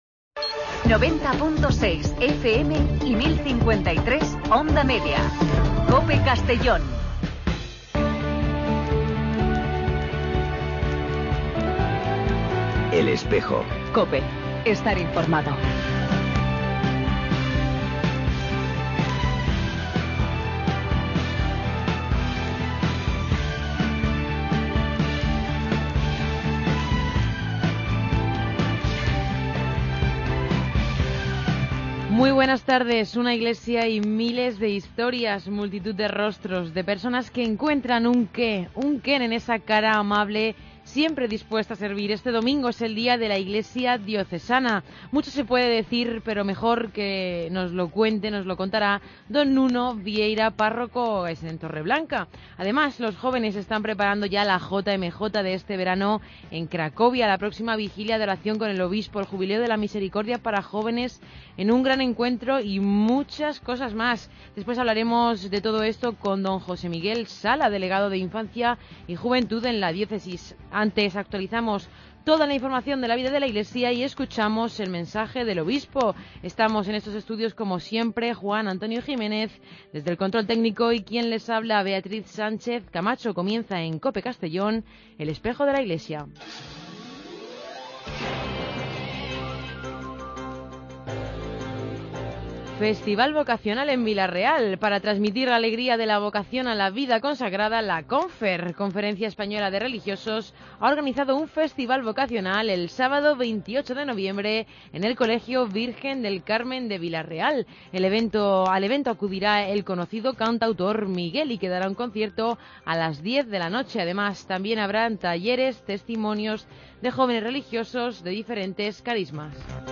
Redacción digital Madrid - Publicado el 13 nov 2015, 18:16 - Actualizado 19 mar 2023, 03:51 1 min lectura Descargar Facebook Twitter Whatsapp Telegram Enviar por email Copiar enlace El programa de radio de la diócesis de Segorbe-Castellón. Con entrevistas, información y el mensaje semanal de monseñor Casimiro López Llorente.